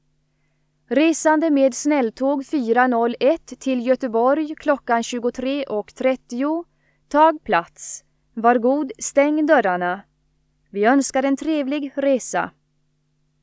Högtalarutrop Stockholms central